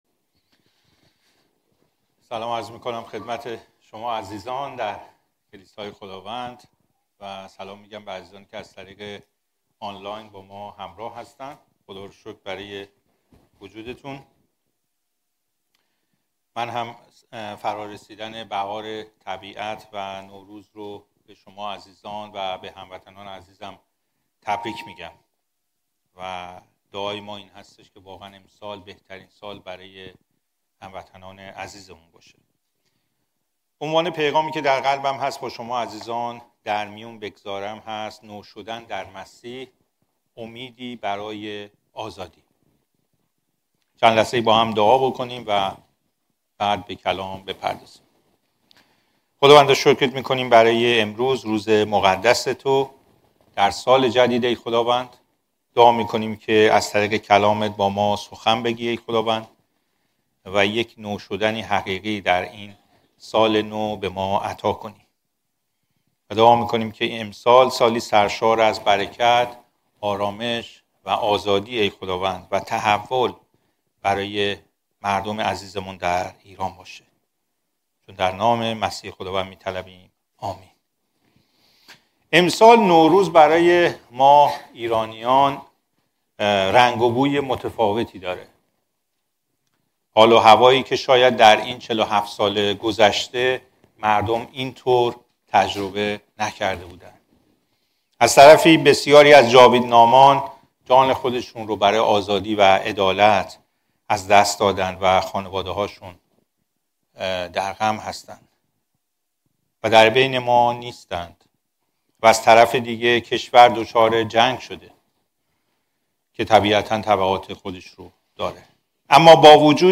موعظه‌ها